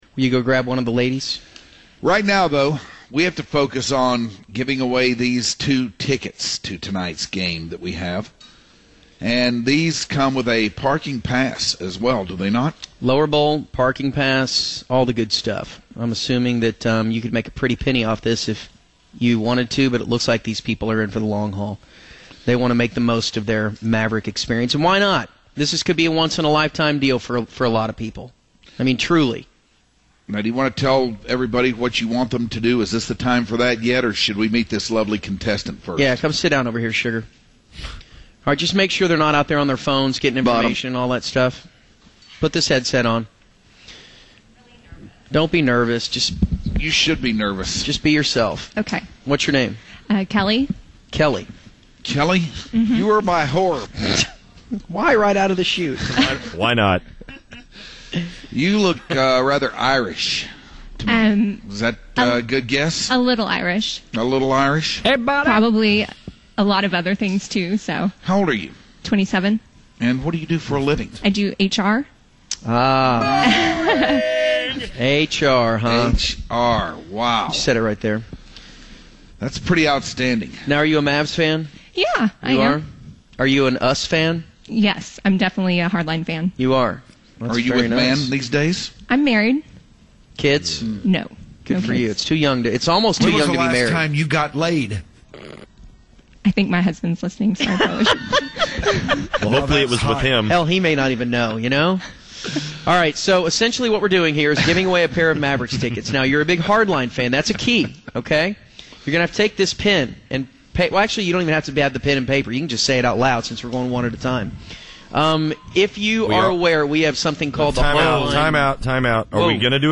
Waaaay back in May, the Hardline played a game where listeners had to guess members of the Hardline Zoo to win Mavs tickets.